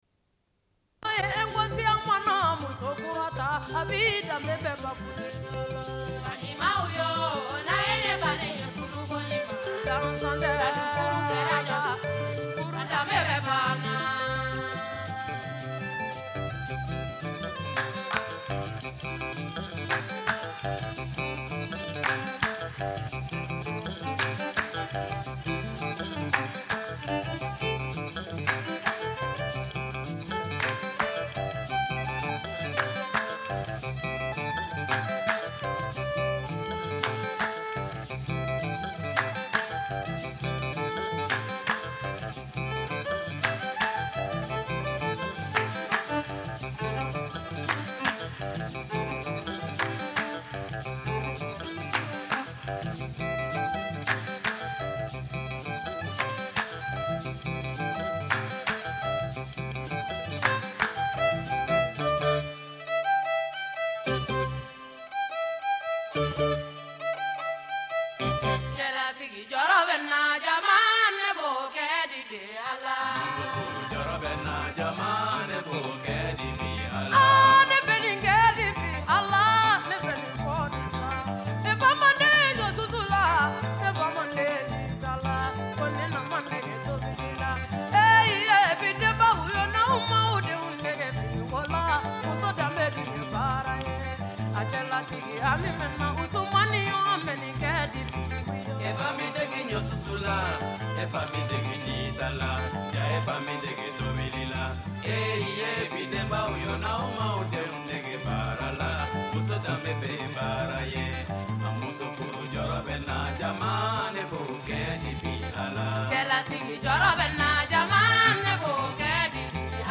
Une cassette audio, face B
Radio Enregistrement sonore